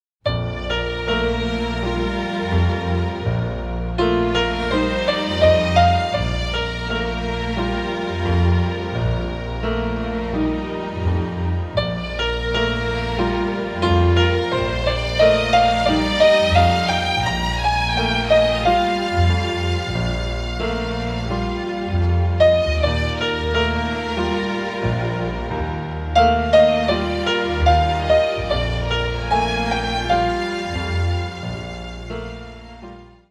With its jazzy elements